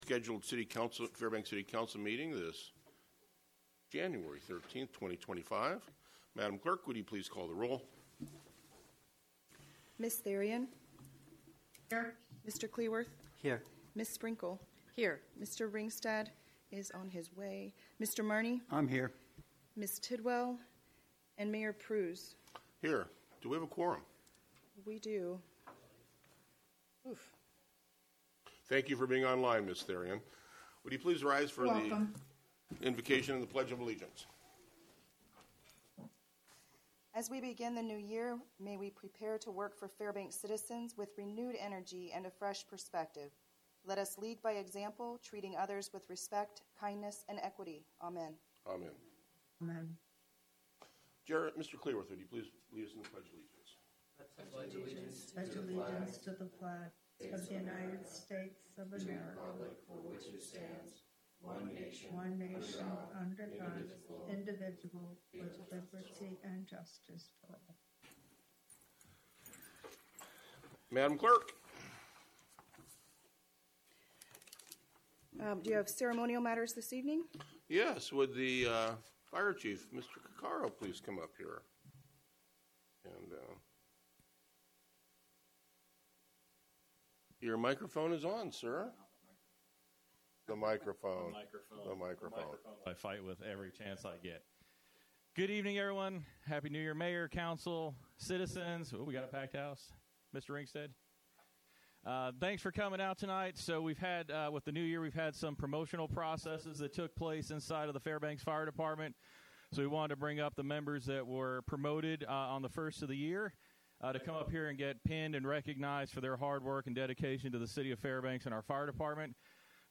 Regular City Council Meeting